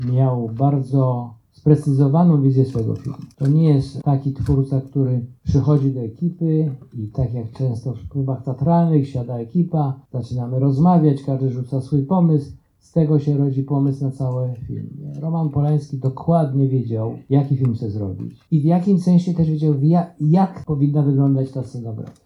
W łódzkim Muzeum Kinematografii odbyło się spotkanie z wybitnym scenografem Allanem Starskim.